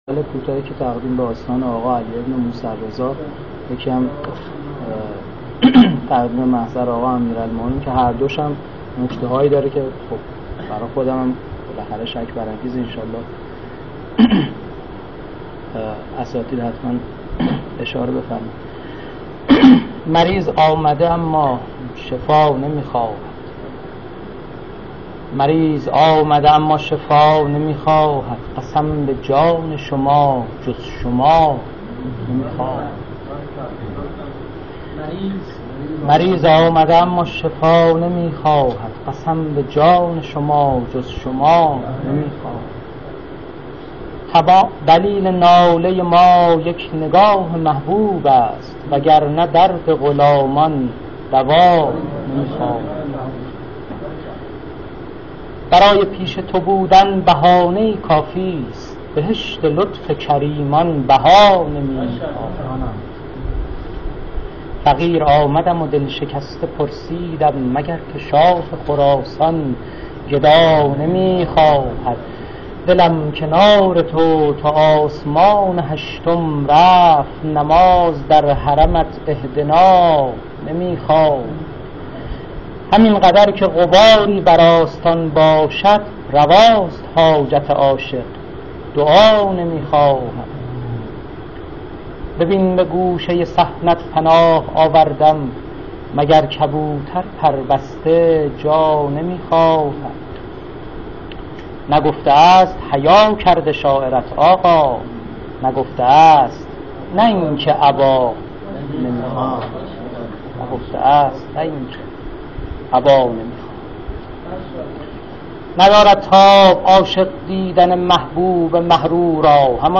جلسه شعر آیینی